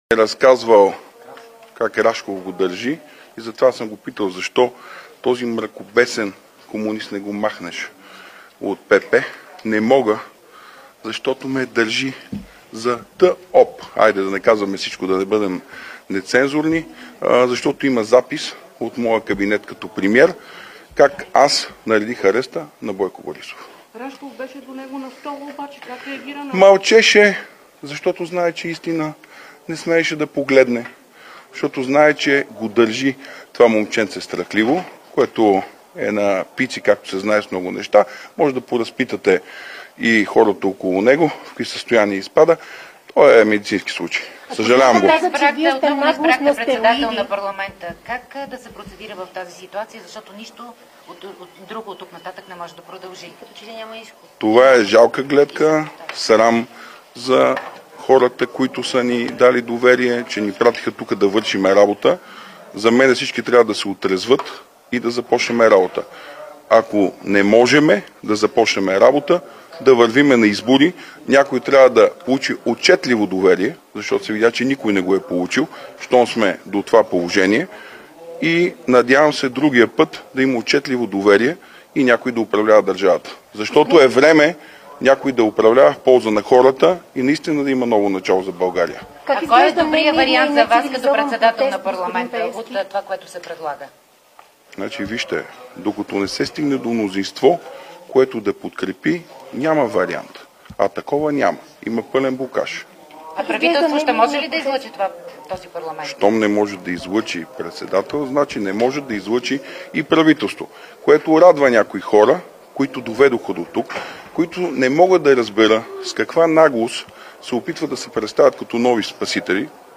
11.35 - Брифинг на съпредседателя на ДПС Делян Пеевски за скандала с Кирил Петков. - директно от мястото на събитието (Народното събрание)